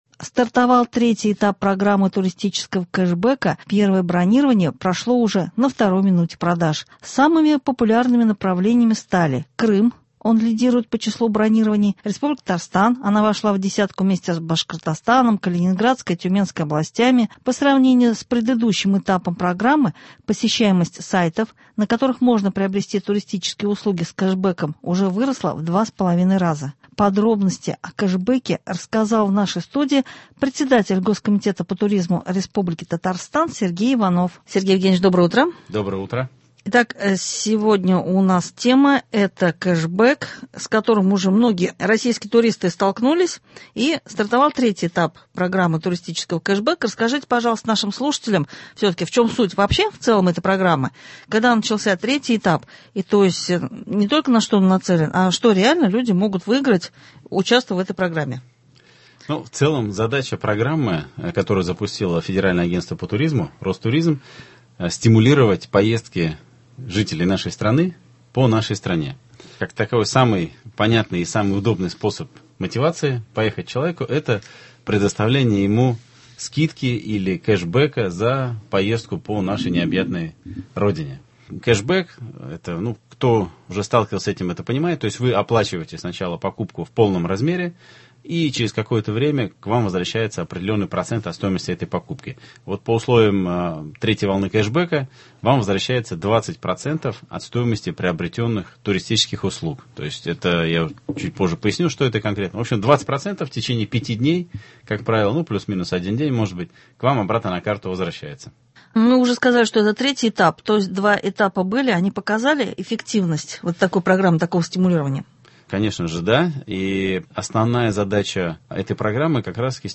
Подробности о кешбэке рассказал в нашей студии председатель Госкомитета по туризму РТ Сергей Иванов.